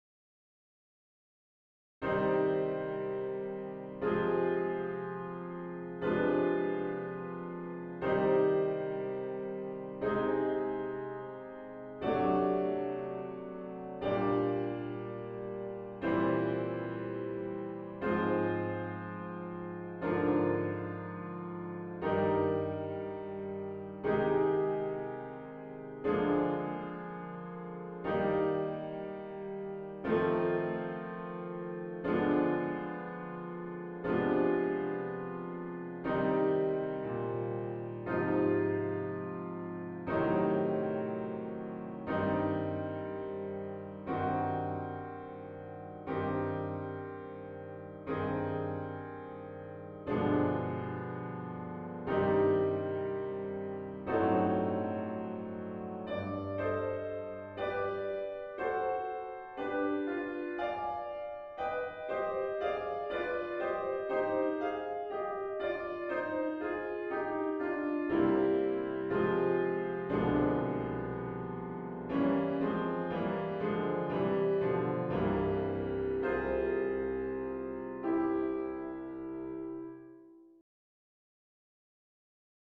Prelude c minor chords
c-minor-prelude-chords.mp3